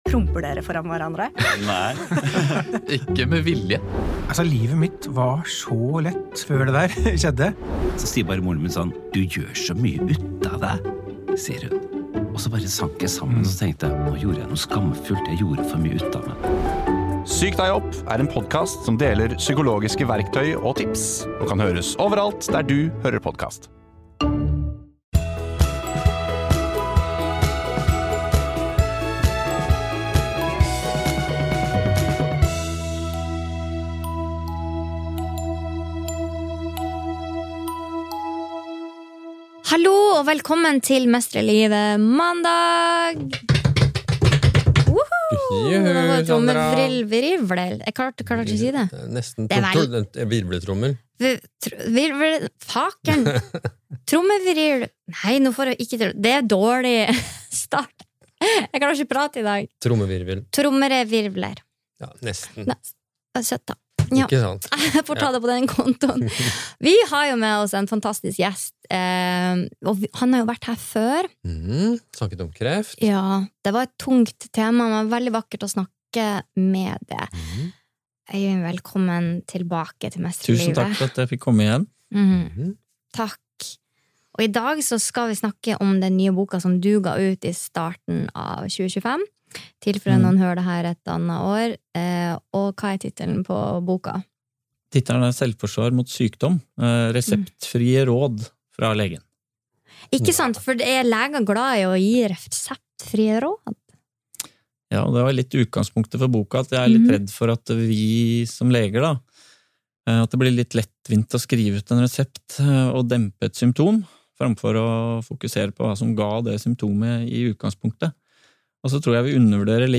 Bonus: Meditasjon om PMS